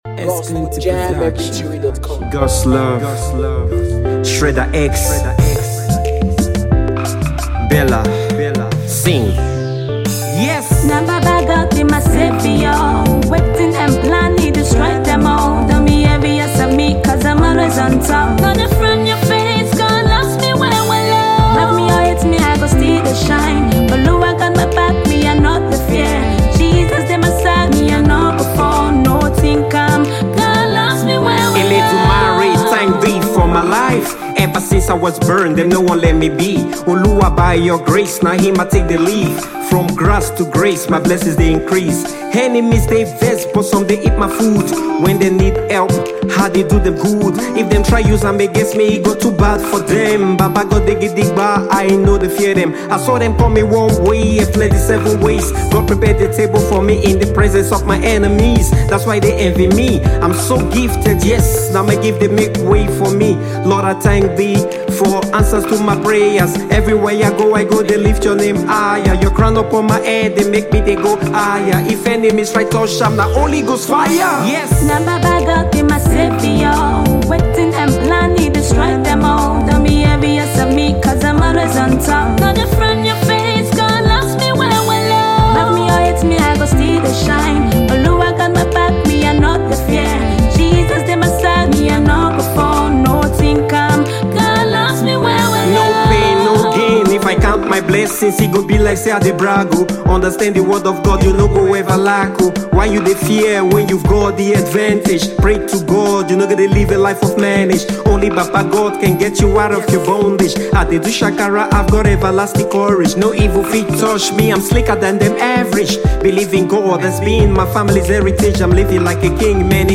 gospel and conscious music hip hop artist